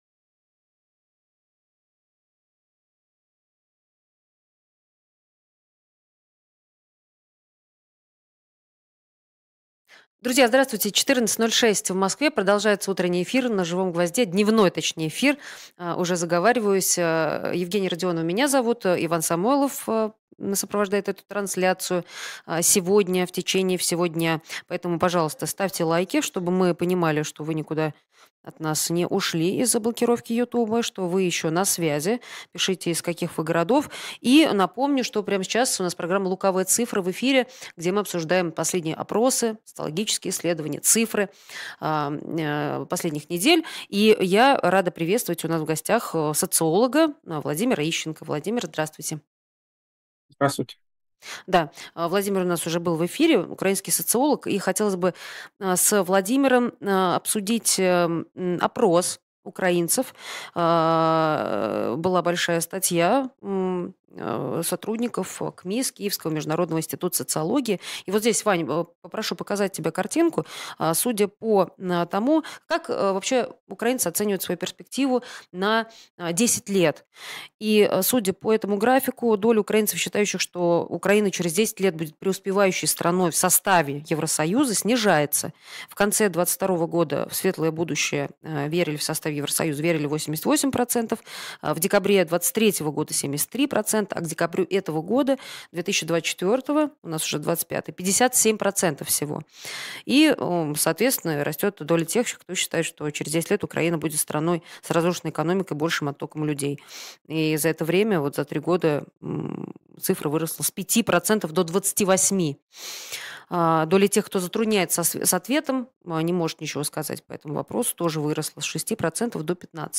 Лукавая цифра 03.01.25 Скачать Подписаться на « Живой гвоздь » Поддержать канал «Живой гвоздь» В эфире программа «Лукавая цифра». Сегодня обсудим: Настроения украинского общества к концу третьего года боевых действий.